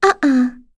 Yuria-Vox-Deny1.wav